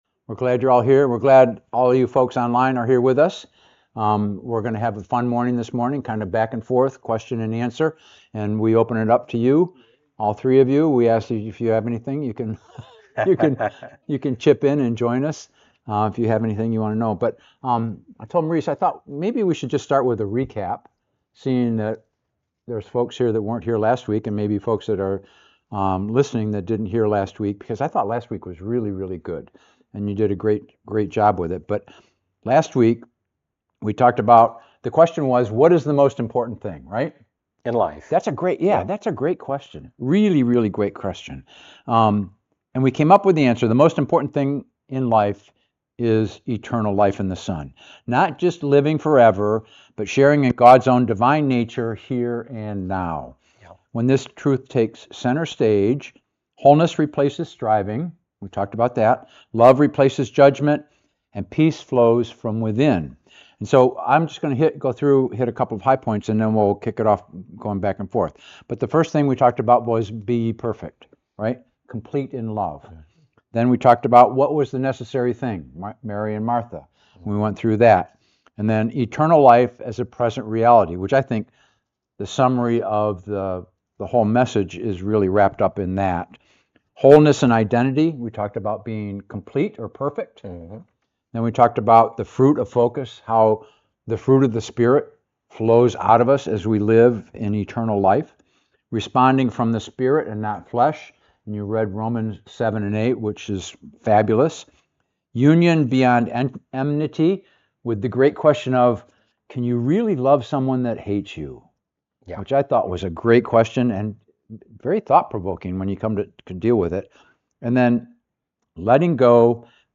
discussing John 17, and eternal life being found in the knowing the Father, and His Son Jesus Christ.